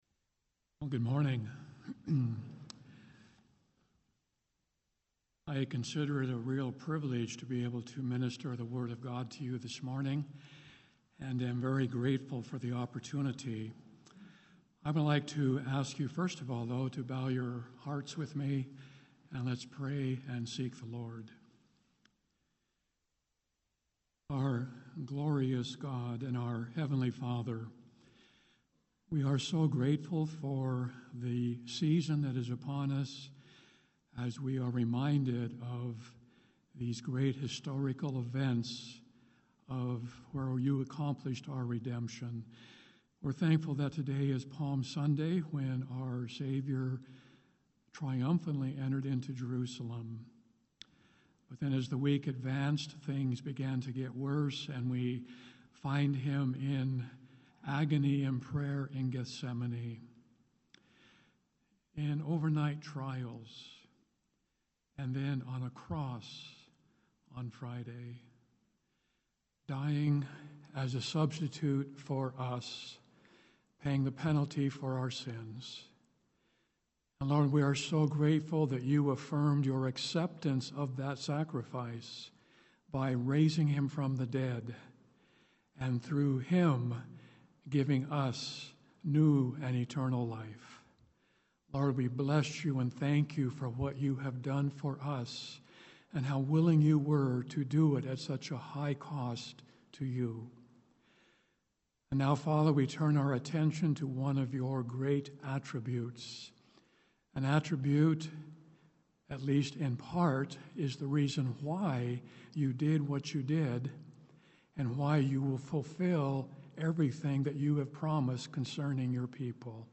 Our God's jealous for us — Audio Sermons — Brick Lane Community Church